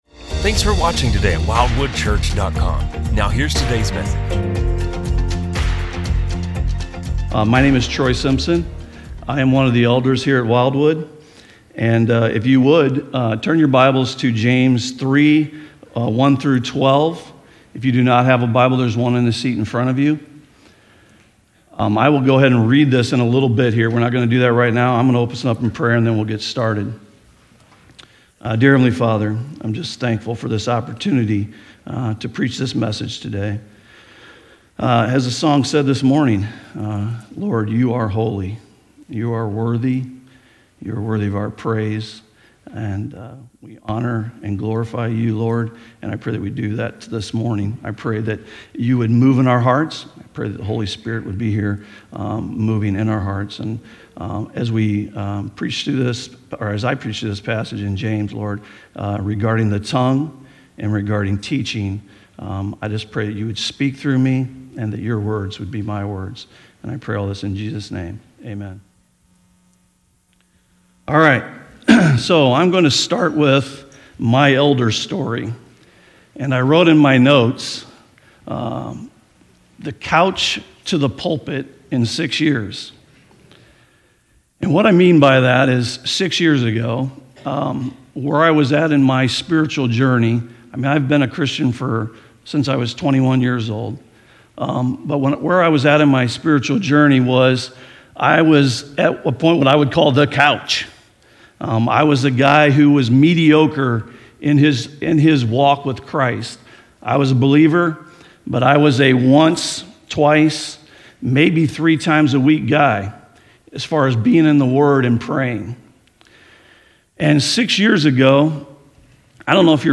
A message from the series "Wisdom From Above."